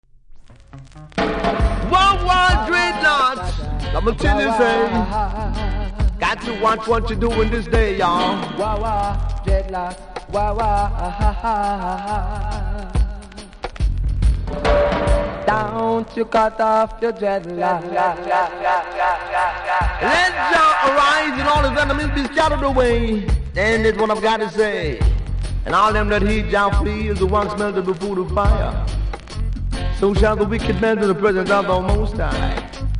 REGGAE 70'S
細かなキズ多めですが音はキズほどでもないので試聴で確認下さい。